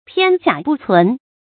片甲不存 注音： ㄆㄧㄢˋ ㄐㄧㄚˇ ㄅㄨˋ ㄘㄨㄣˊ 讀音讀法： 意思解釋： 一片鎧甲都沒保存下來。形容全軍覆沒。